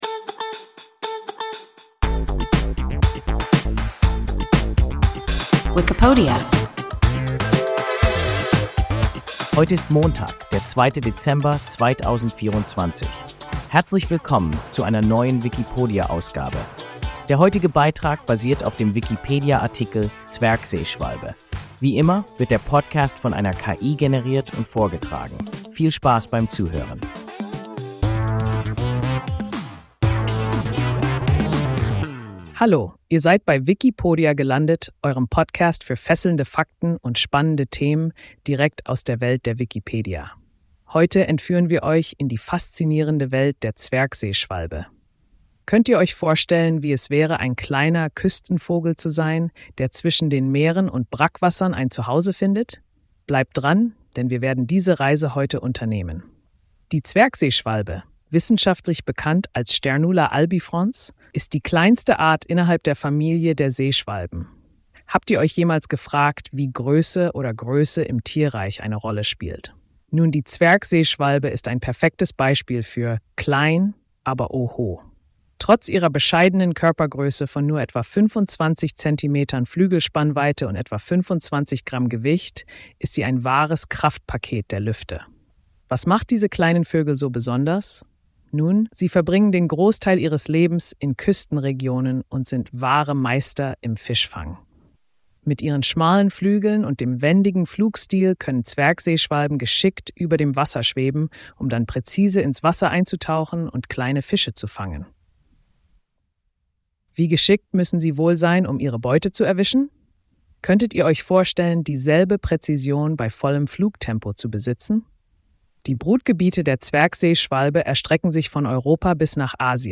Zwergseeschwalbe – WIKIPODIA – ein KI Podcast